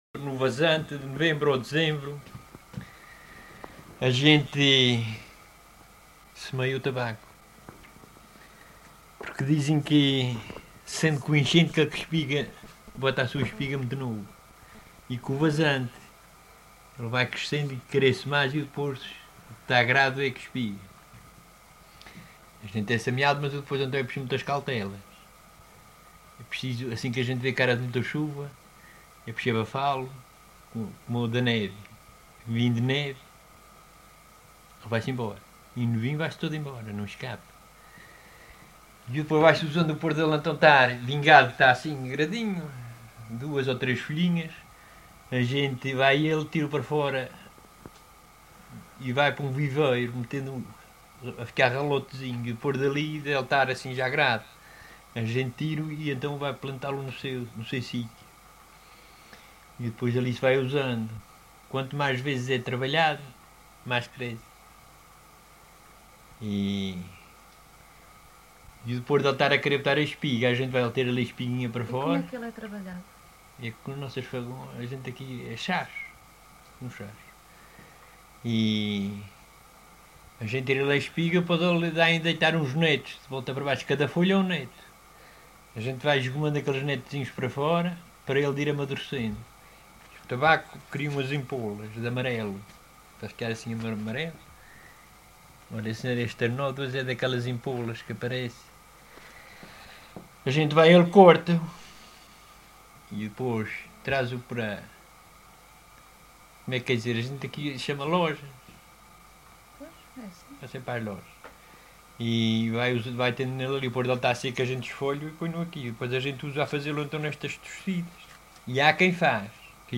LocalidadeBandeiras (Madalena, Horta)